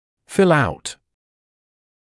[fɪl aut][фил аут]заполнять (документ, анкету и пр.)